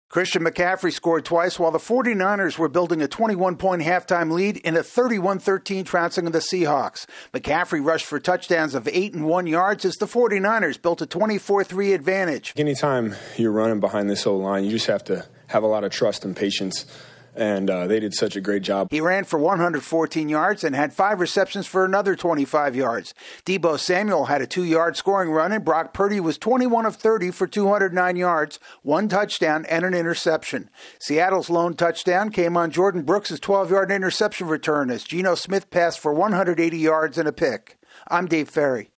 The 49ers dominate in a win over the Seahawks. AP correspondent